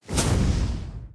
Fireball 2.ogg